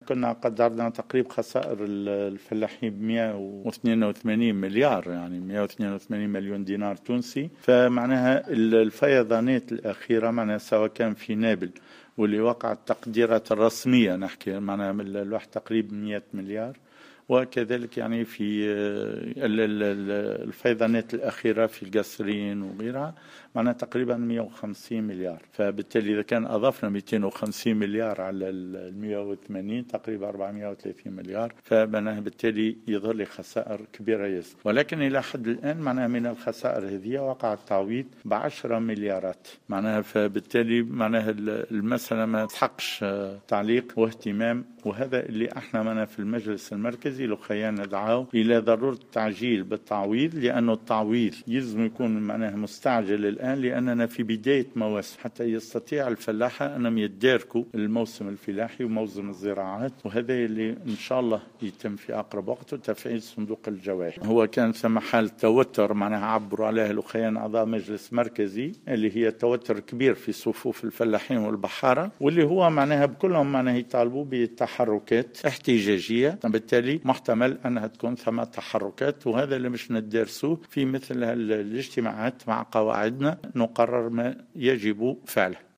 في تصريح لمراسلة "الجوهرة اف أم"